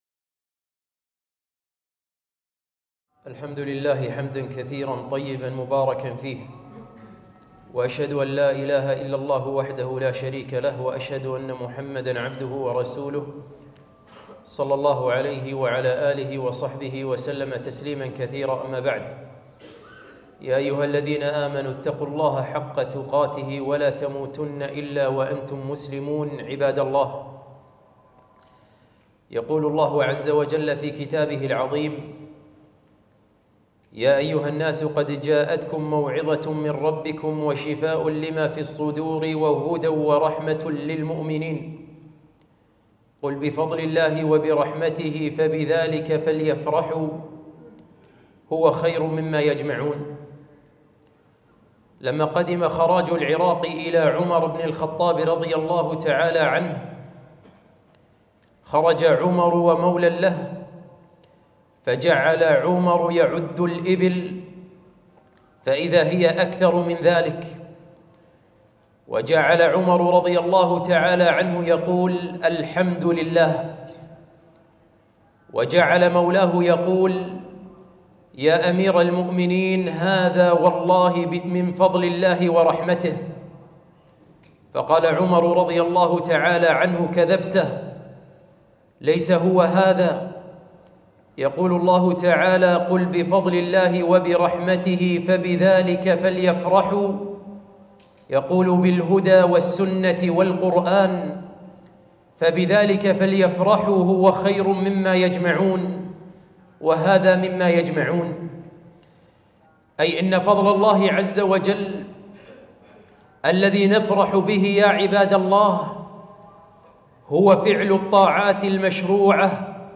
خطبة - الفرح بسد الخلل